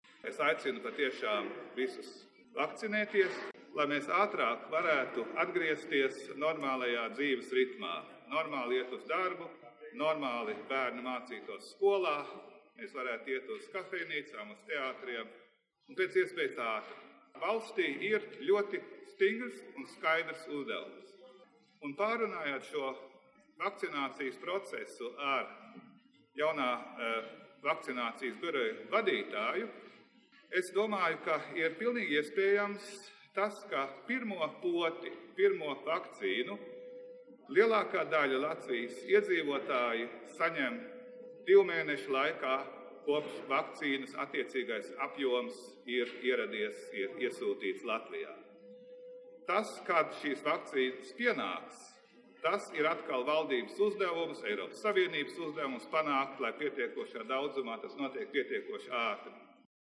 Pēc vakcīnas saņemšanas Valsts prezidents Egils Levits: